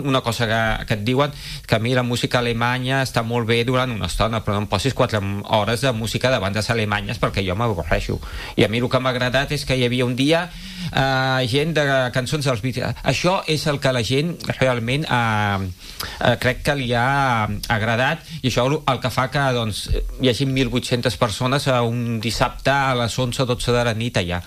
en una entrevista al magazine A l’FM i + de Ràdio Calella TV